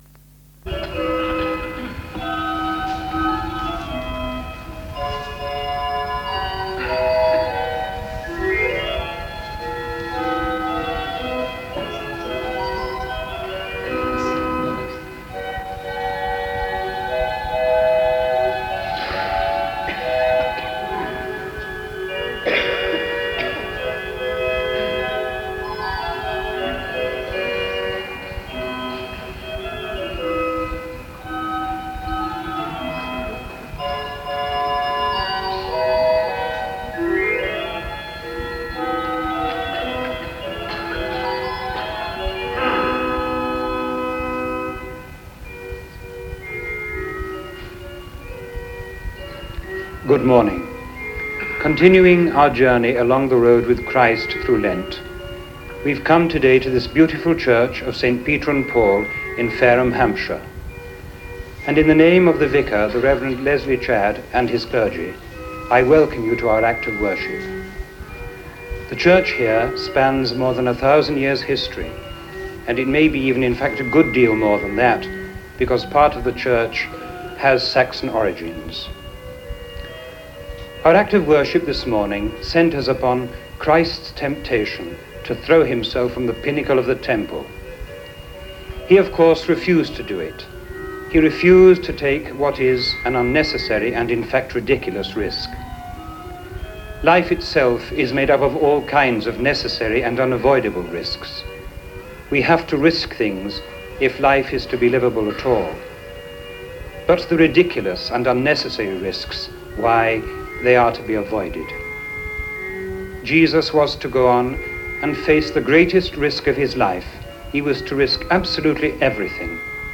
An ITV live programme audio is available at the bottom of the page.